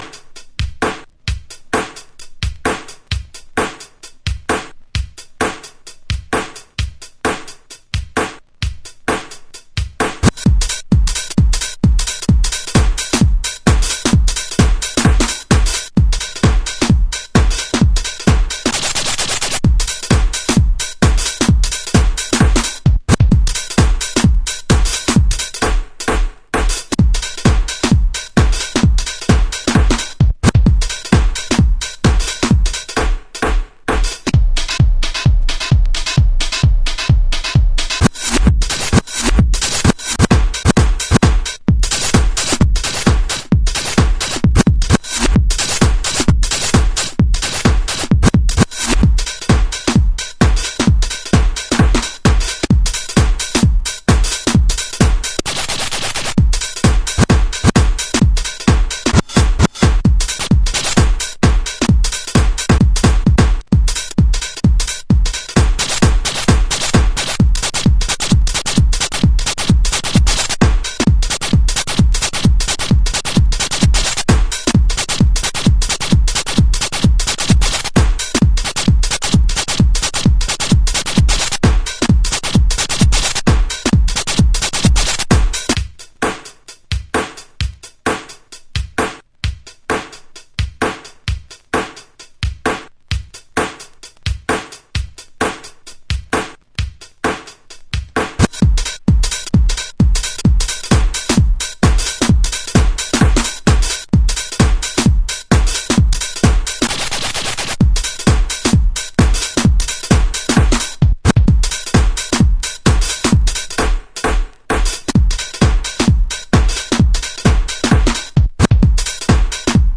House / Techno
センス溢れる音の抜き差しと随所にブチ込まれるスクラッチとTR909のビートが単純にカッチョいい。
デュクデュクのチキチキです。